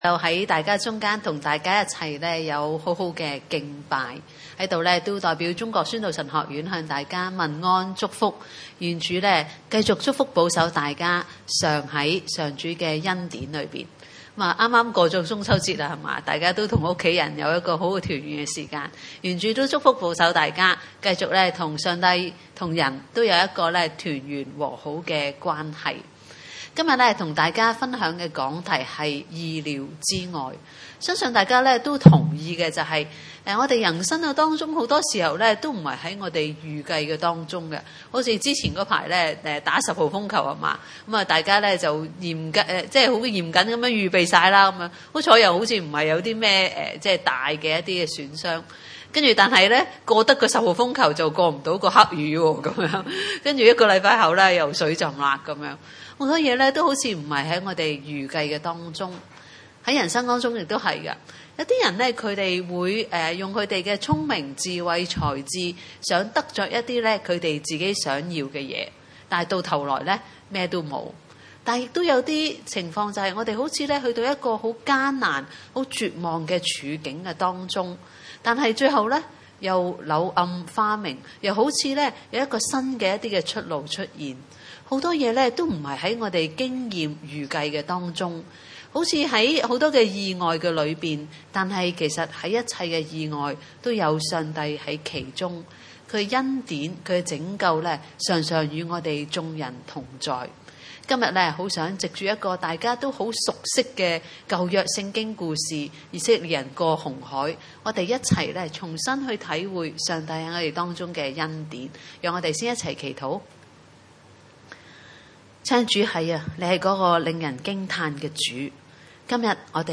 經文: 出埃及記 14:1-4、30-31 崇拜類別: 主日午堂崇拜 1 耶和華吩咐摩西說： 2 你吩咐以色列人轉回，要在比‧哈希錄前 面，密奪和海的中間，巴力‧洗分的前面 安營。